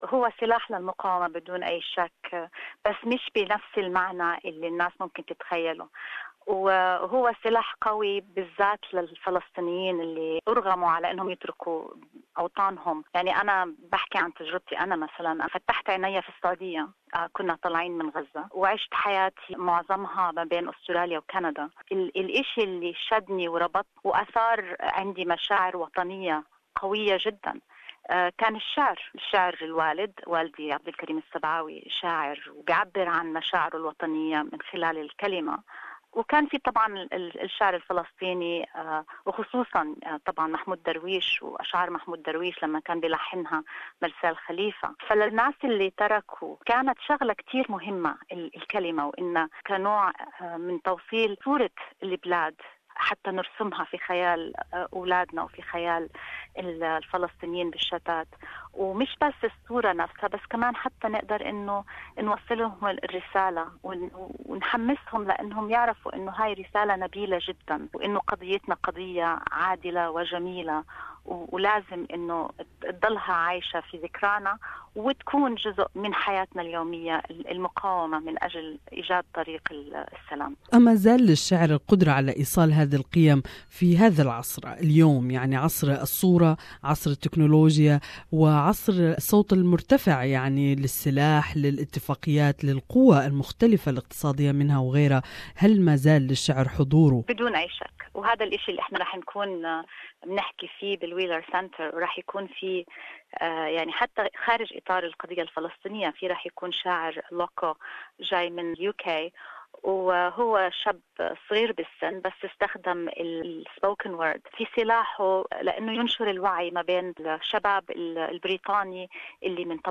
More in this interview